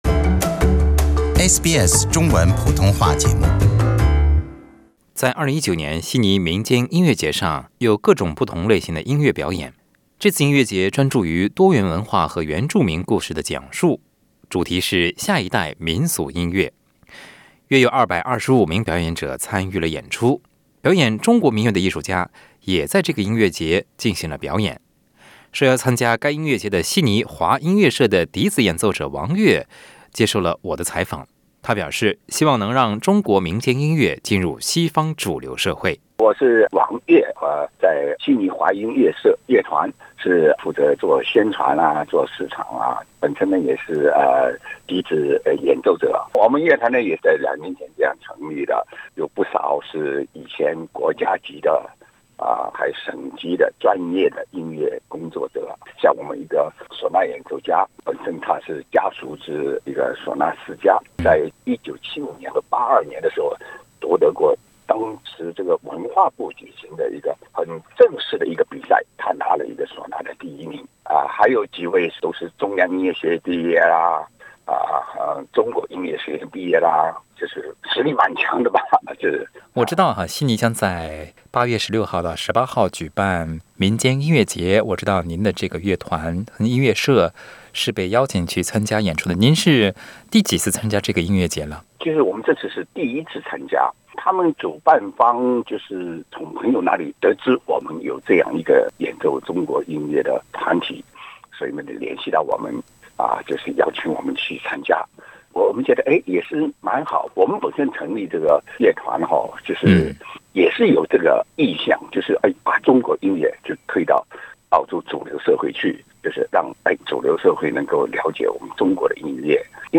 请您点击收听详细的采访报道。